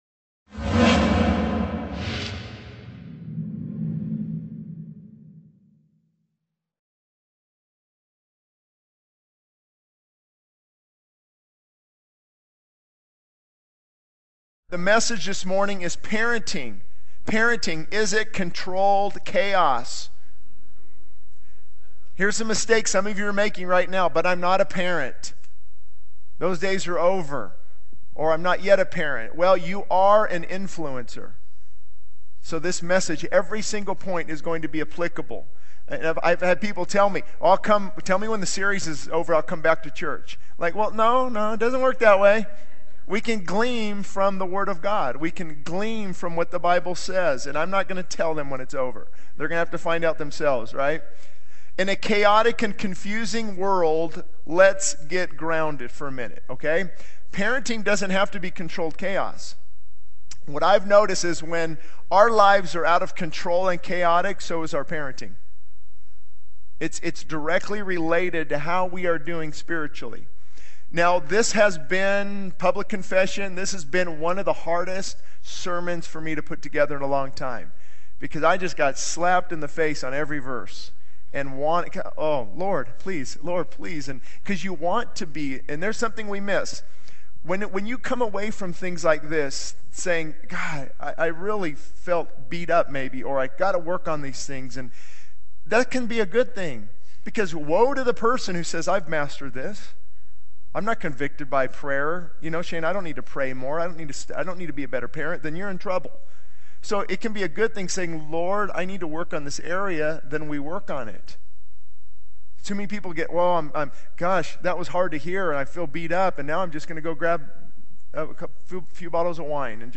This sermon emphasizes the importance of parenting, highlighting the need for intentional and consistent guidance based on biblical principles. It addresses the challenges parents face in a chaotic world, the significance of disciplining children with love and wisdom, and the impact of parental influence on children's well-being and future. The message encourages parents to prioritize their relationship with God, invest time in teaching their children the ways of the Lord, and make necessary changes to strengthen family bonds.